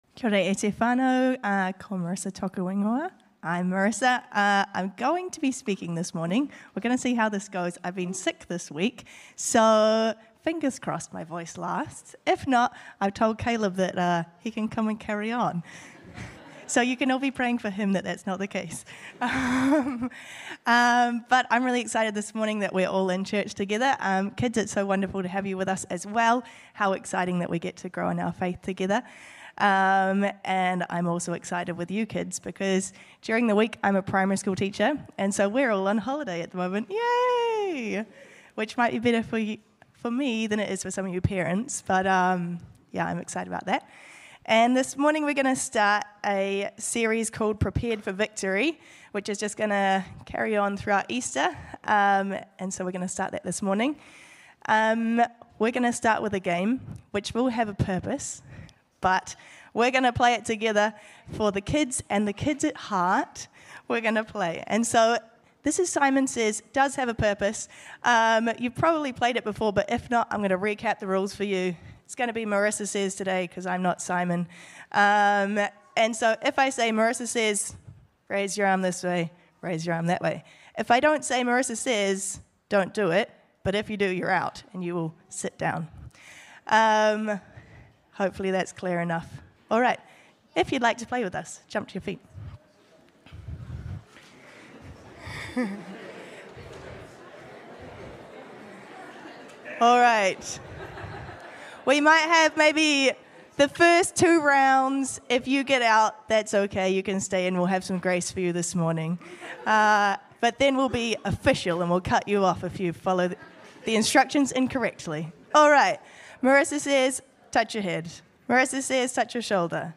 2025 Current Sermon Prepared Before The Battle Prepared for Victory!